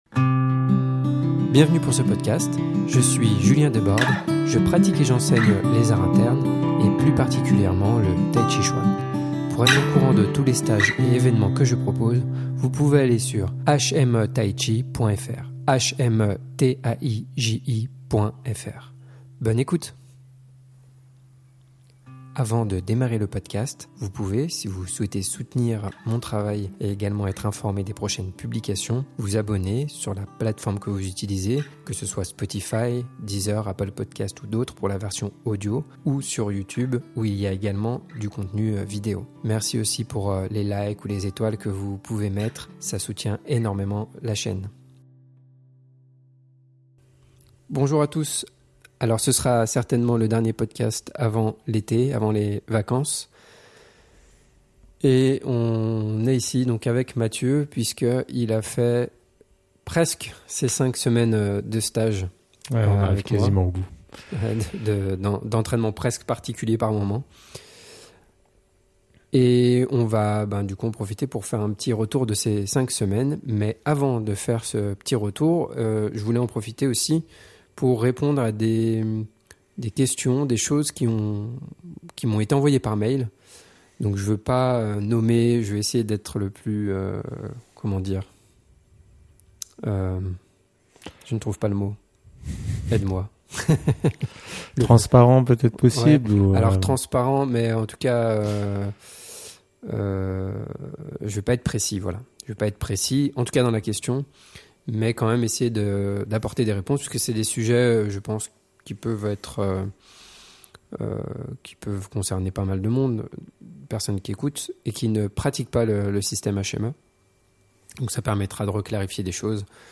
Discussion Tai Chi #11